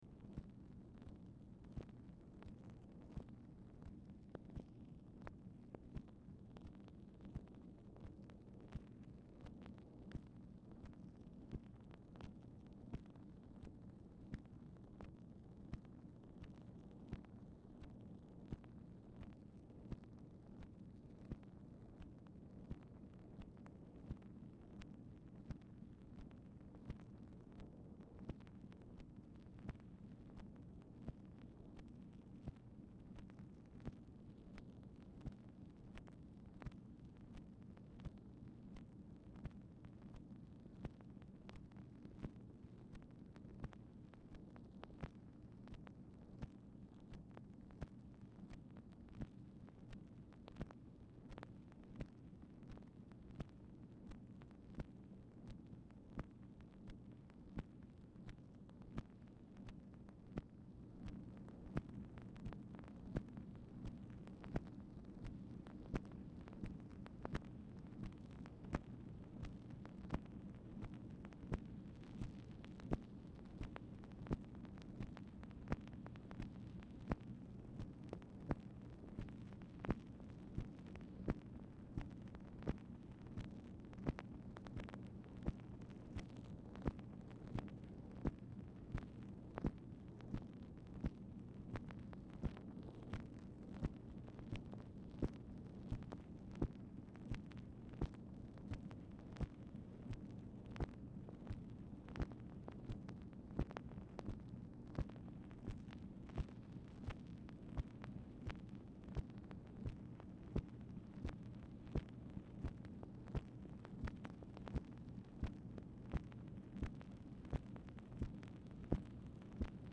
Telephone conversation # 10426, sound recording, MACHINE NOISE, 7/25/1966, time unknown | Discover LBJ
Format Dictation belt
Specific Item Type Telephone conversation